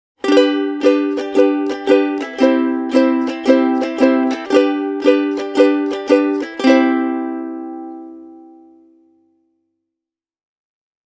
Aquila Nylguts tend to produce a crisp, bright and open sound, which is why they can be a good choice for darker sounding ukuleles (like many plywood-bodied instruments).
Fluorocarbon strings tend to sound meatier and punchier compared to Nylgut strings.
The audio clips have been played on a pair of Martin Style 2-type sopranos – a Sigma SUM-2S (Supernylgut) and an Ohana SK-38 (fluorocarbon).
nylgut-vs-fluorocarbon-e28093-supernylgut.mp3